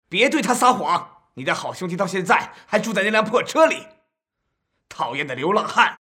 Film & TV VO
“Dubbed Films Compilation – Male” All audio samples below are original excerpts from final soundtracks, featuring male characters across age groups—from childhood to senior years.
In this excerpt, Tony confronts his alcoholic father. The voice actor channels raw emotional intensity to vividly portray the character’s anger and inner conflict.